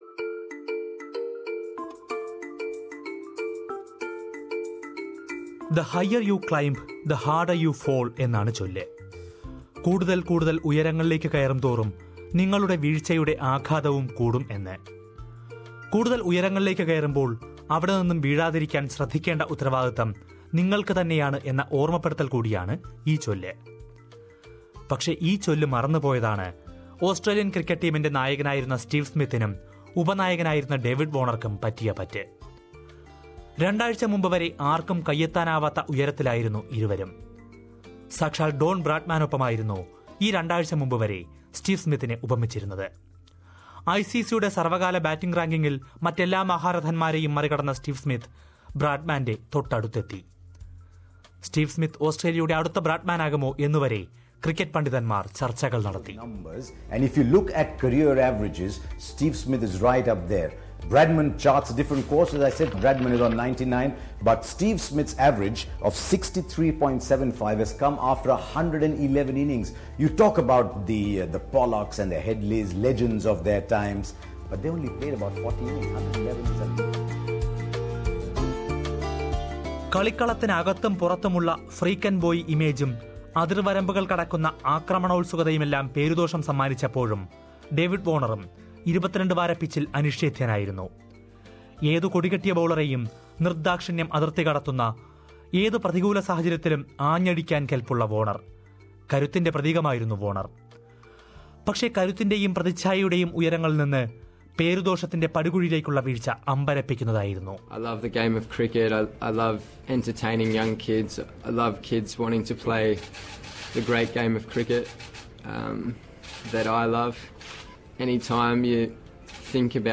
Listen to a report about the questions that remain unanswered even after the press conferences by those involved in the ball tampering scandal.